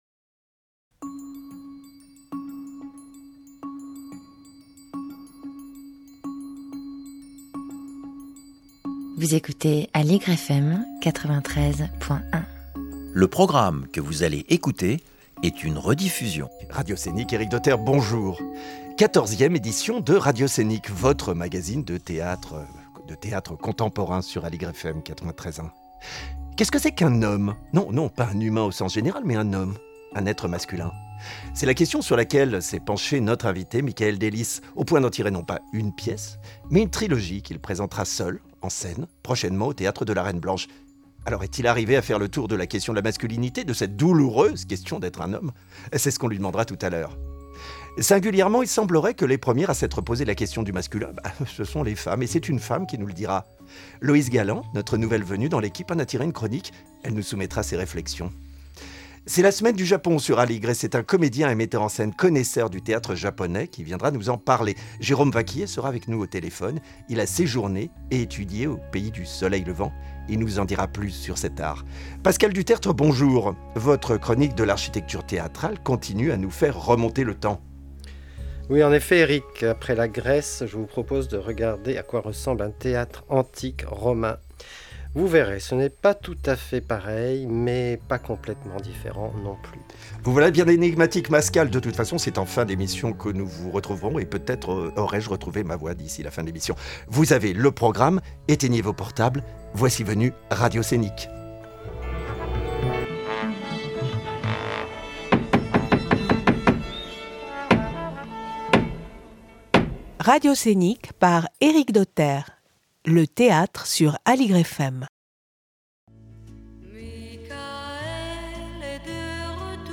Nos extraits sonores et musicaux :